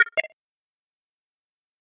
Звуки загрузки файла
Звук файла: скачивание завершено